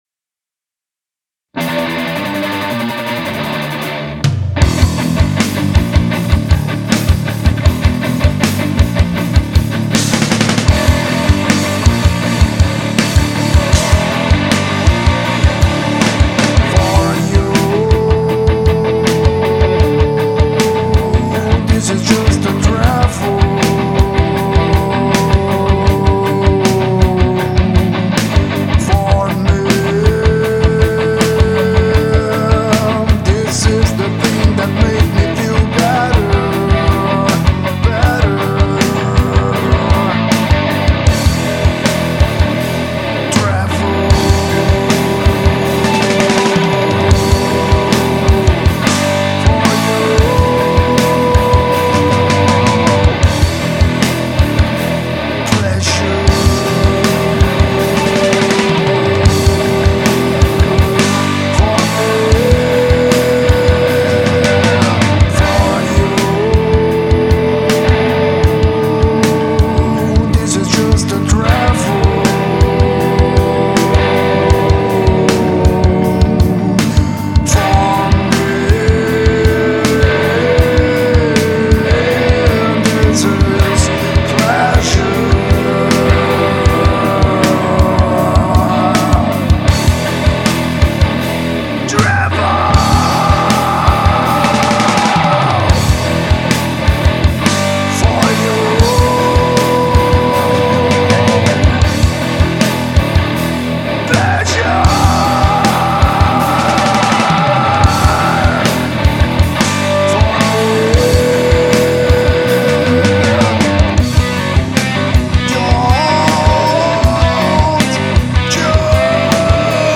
EstiloRock